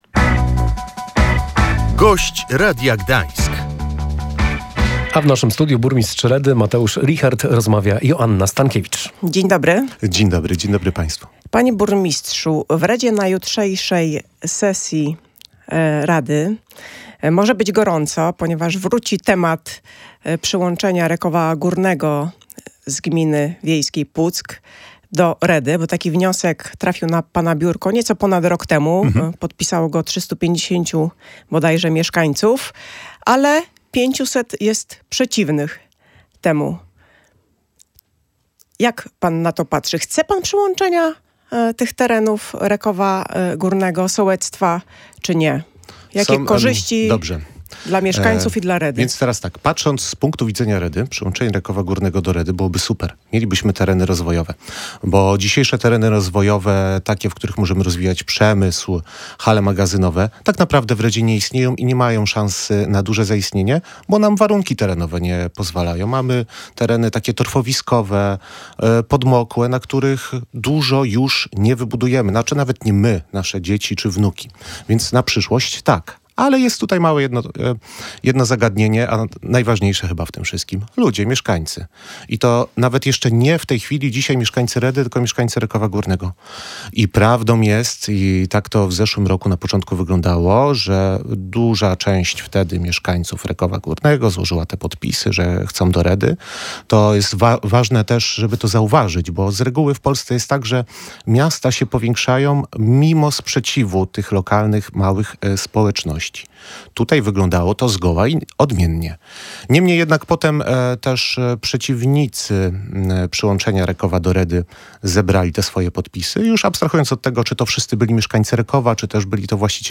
Jak mówił na naszej antenie Mateusz Richert, burmistrz Redy, trudno mówić o rozszerzaniu granic przy sprzeciwie ludzi. Poranny gość Radia Gdańsk podkreślił, że osób, które są przeciwne jest więcej i trzeba liczyć się z ich zdaniem.